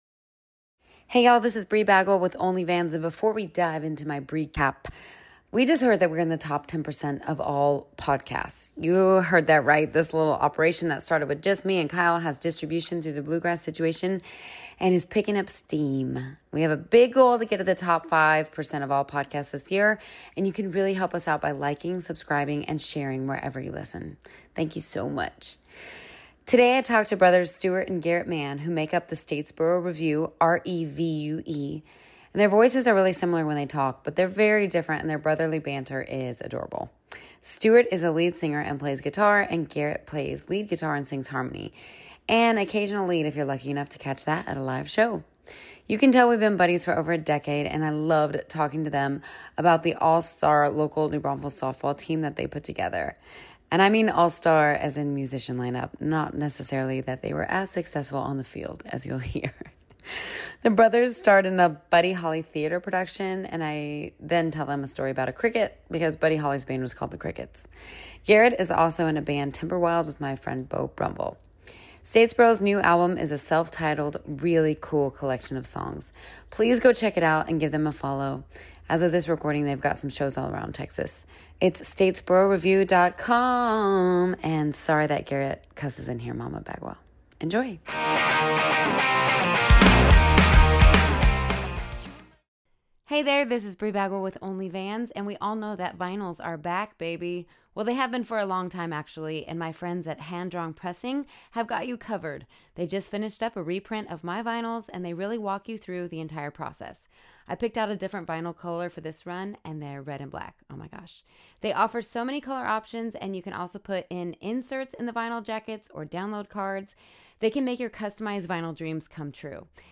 Their voices are very similar, but they’re very different, and their brotherly banter is adorable.